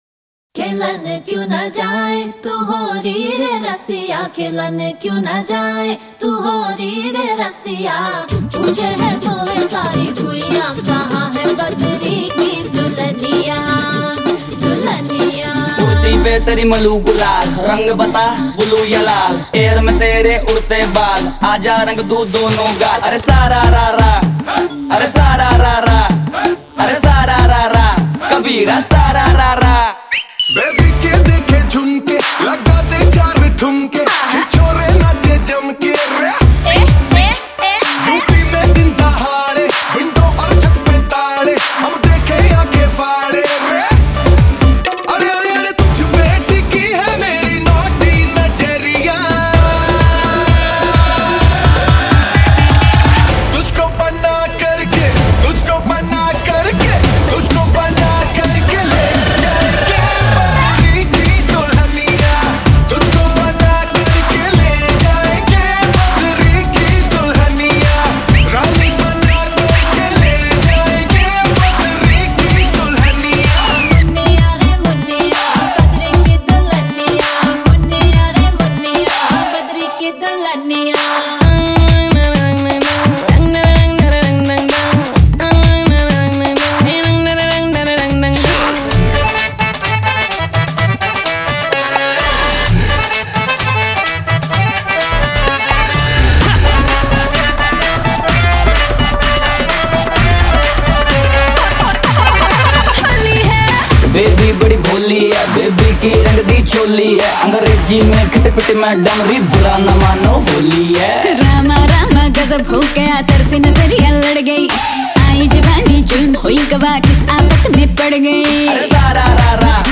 آهنگ شاد هندی